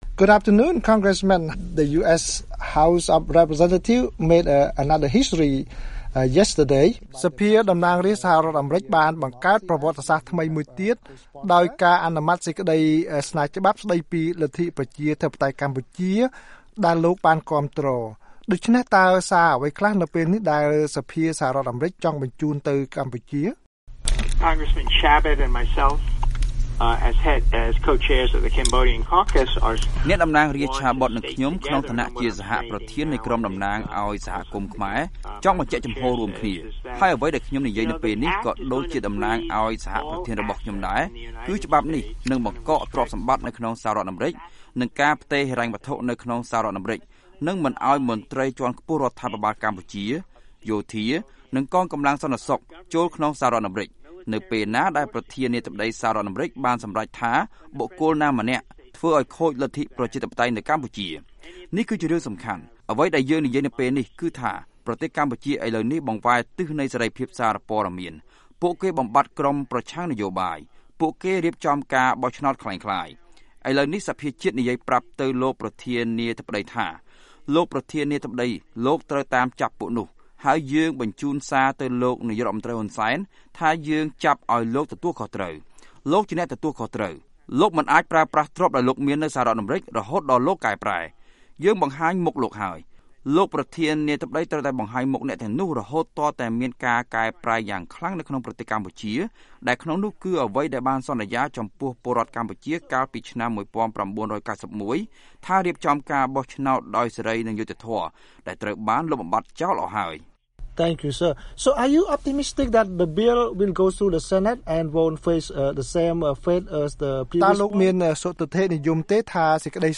បទសម្ភាសន៍ VOA៖ លោក ឡូវឹនសល ថា ដល់ពេលប្រើវិធានការក្តៅដាក់លោក ហ៊ុន សែន